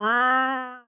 chrabaszcz11.mp3